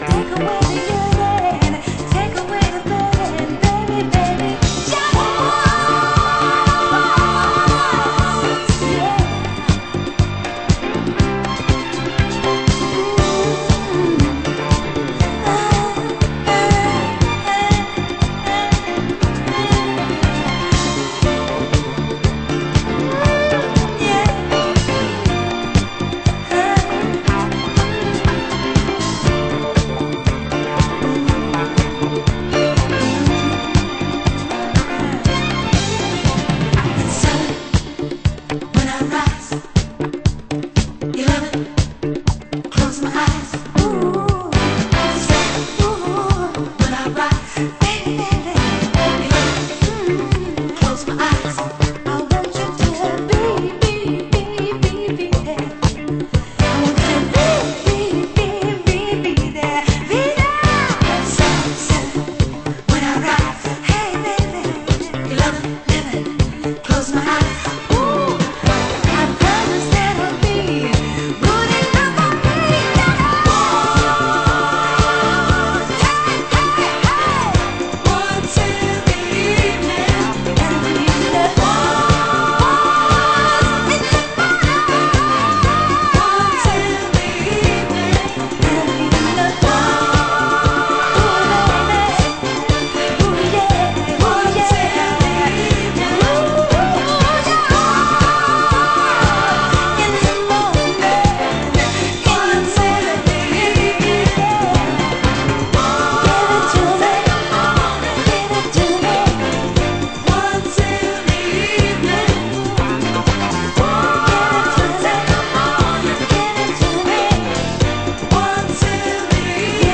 盤質：軽いスレ有/少しチリパチノイズ有